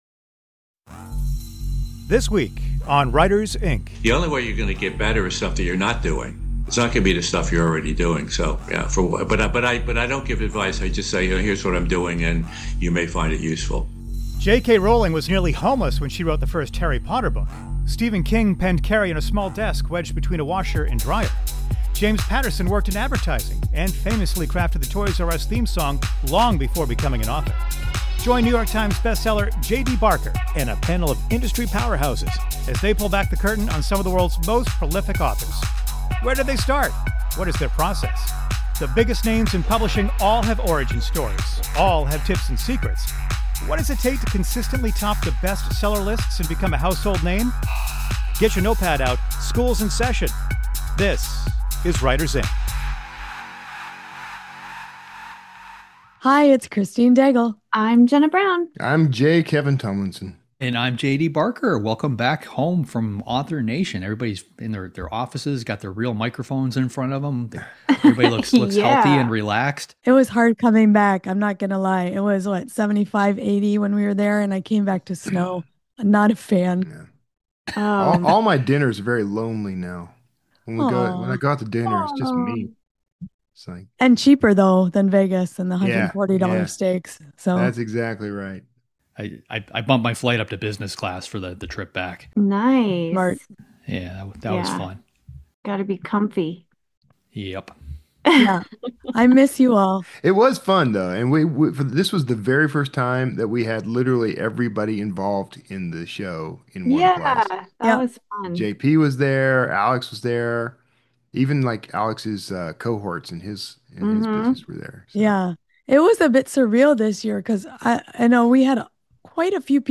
Then, stick around for a chat with James Patterson!